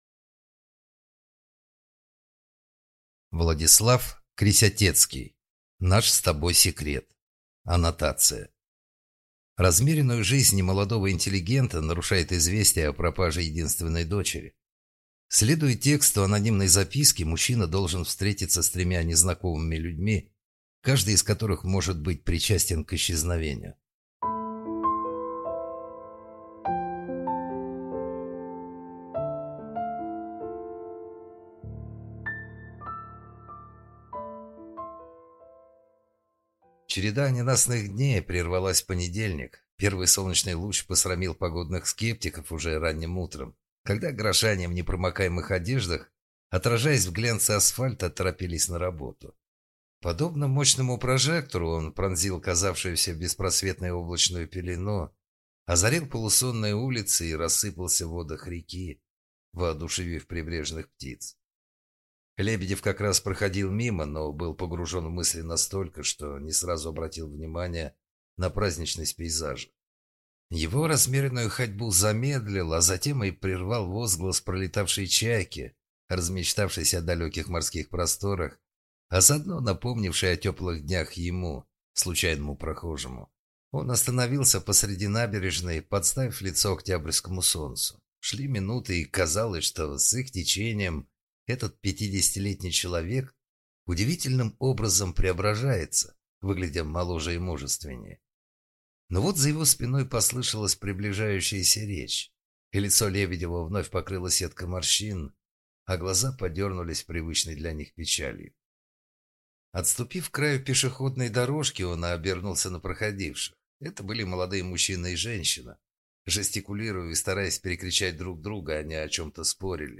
Аудиокнига Наш с тобой секрет | Библиотека аудиокниг